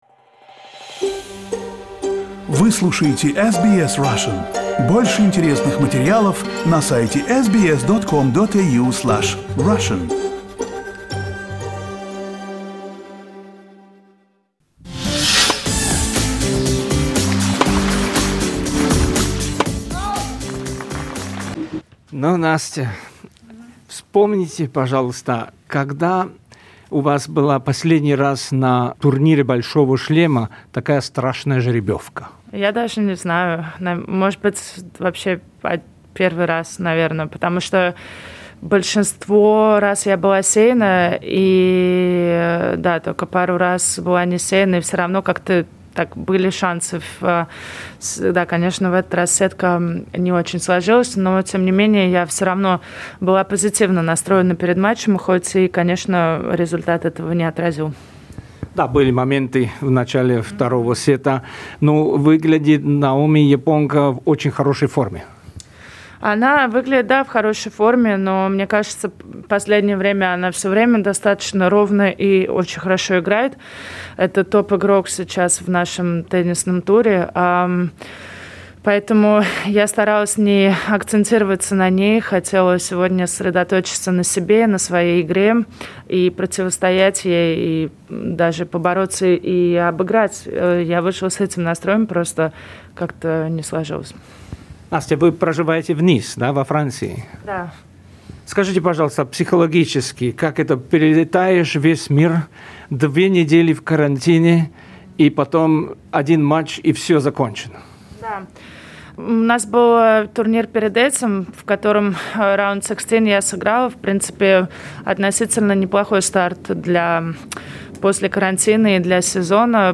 Interview with the Russian tennis player Anastasia Pavlyuchenkova.